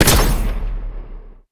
ZombieSkill_SFX
sfx_skill 02_1.wav